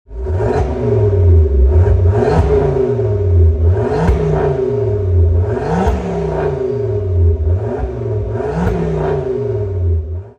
• 2.5L Inline 5-Cylinder Turbocharged Petrol Engine
Listen to the guttural growls of this Stage 5 brute!
• KMS MotorSport ‘Thunderstorm’ 3inch Cat-Back Exhaust System: Resonated (£935)
ford-focus-rs-mk2-frozen-white-revo-stage-5-516ps-exhaust-revs-PPF.mp3